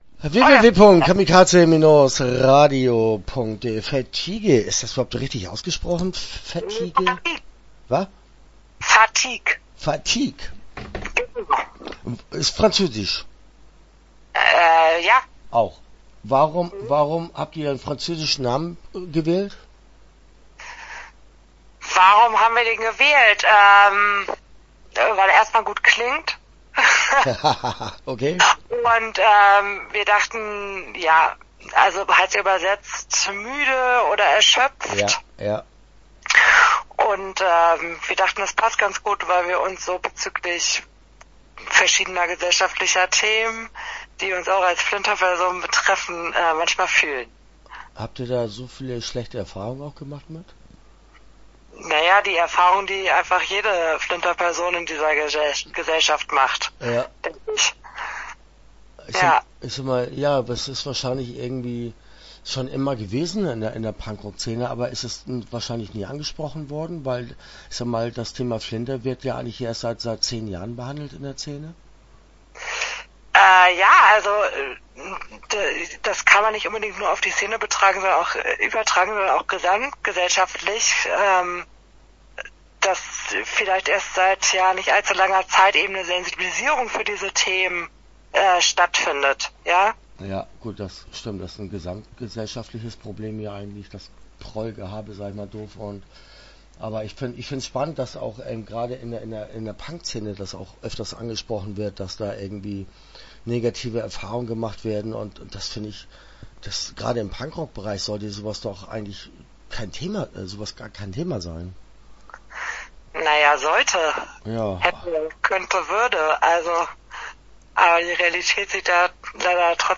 Start » Interviews » Fatigue